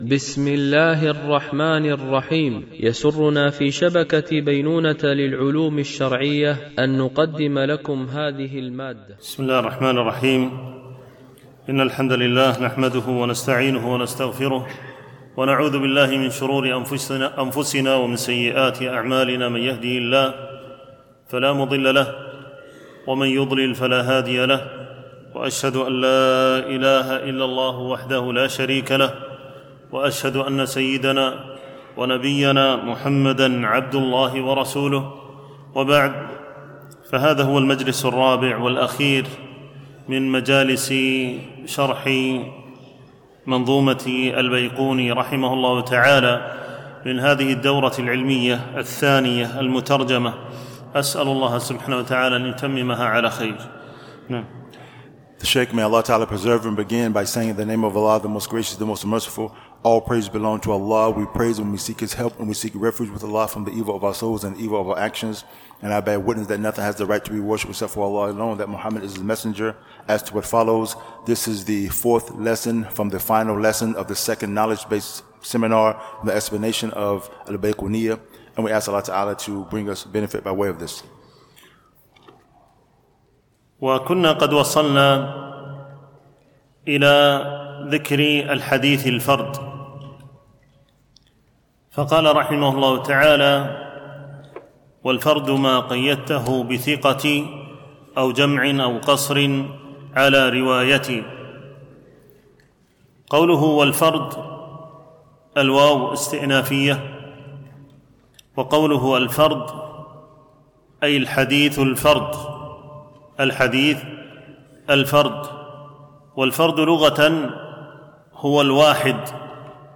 الدورة العلمية الثانية المترجمة للغة الإنجليزية، لمجموعة من المشايخ، بمسجد أم المؤمنين عائشة رضي الله عنها
MP3 Mono 44kHz 96Kbps (VBR)